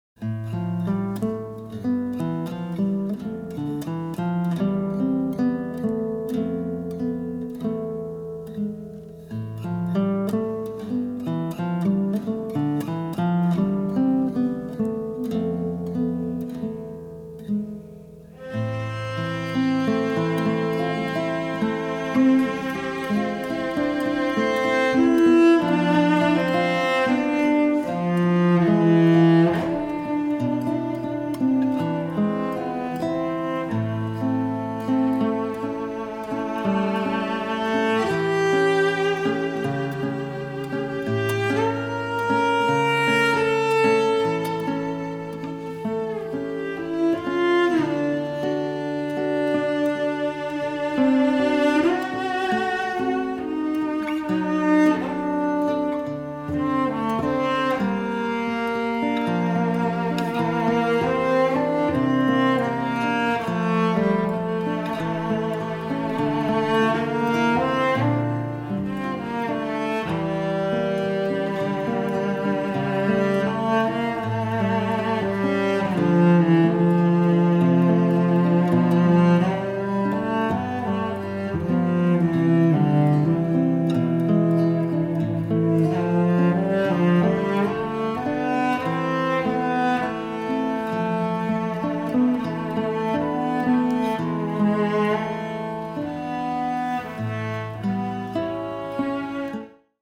★ 吉他與大提琴的完美珍稀組合，展現悠揚樂韻！
★ 豐富飽滿的共鳴、清脆透明的絕佳音響效果！